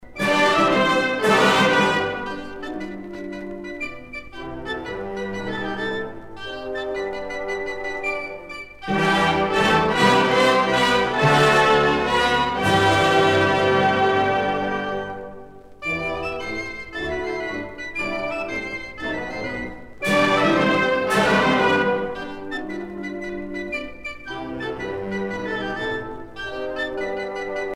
Genre laisse
Pièce musicale éditée